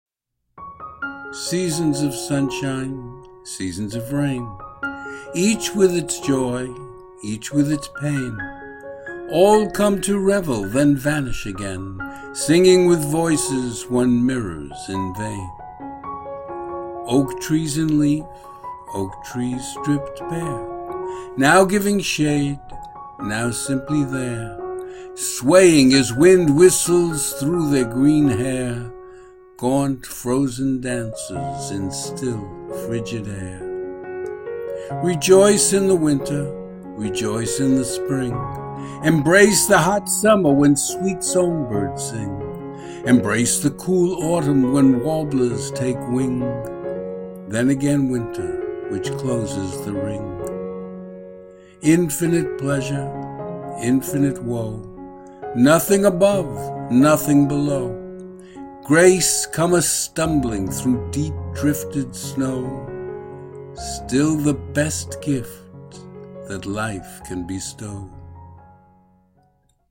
Video and Audio Music: